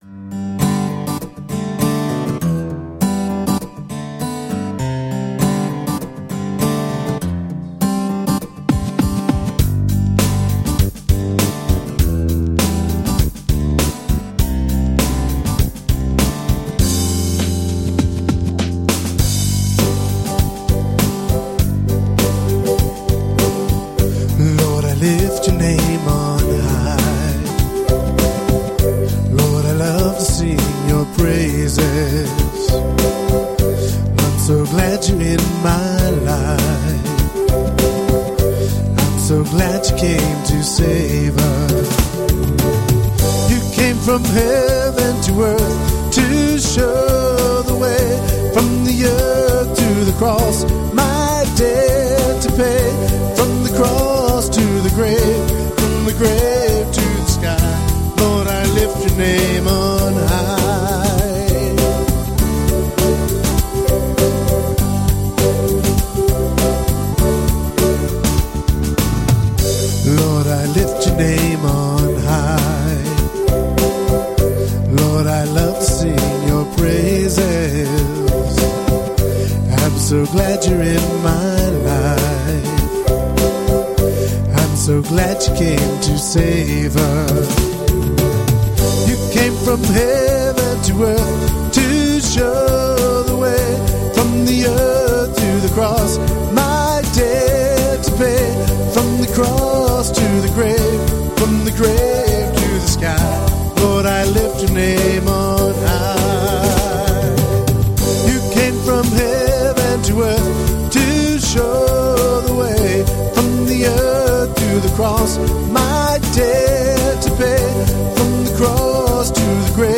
Sunday Arizona Church Service 02/22/2015 | The Fishermen Ministry